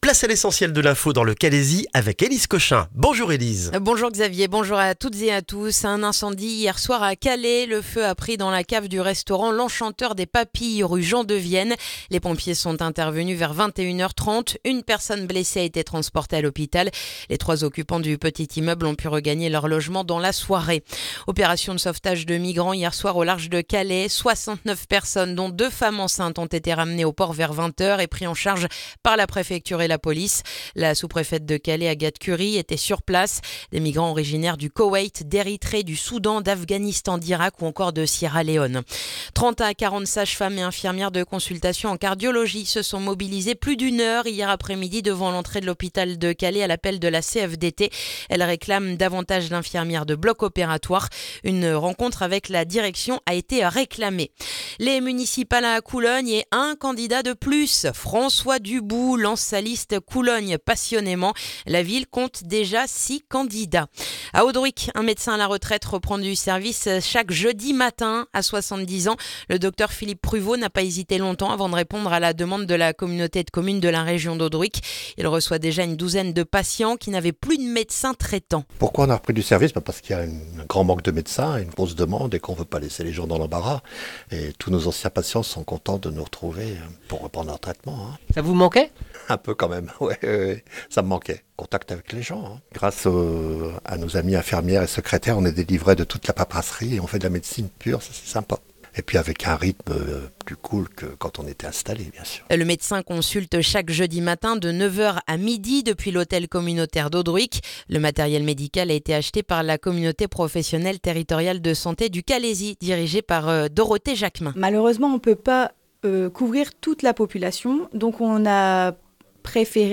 Le journal du mardi 10 février dans le calaisis